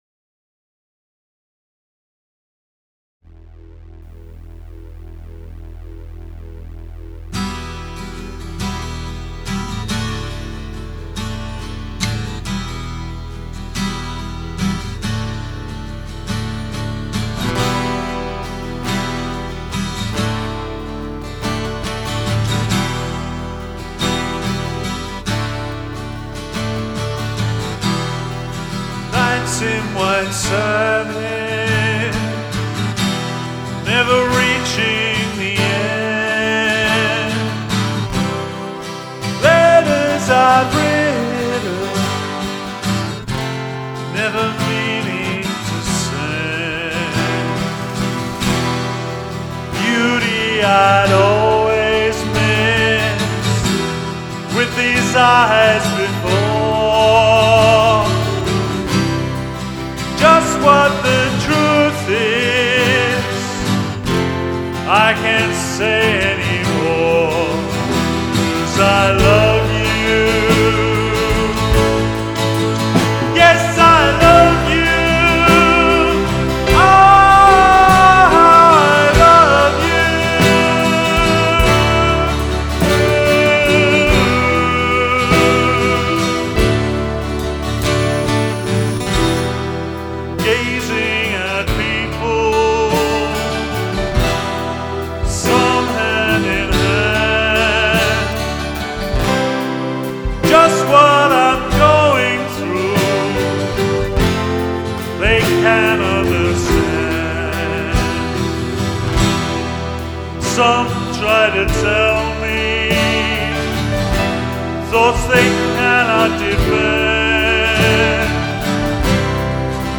goes all mean and moody with the vocals one...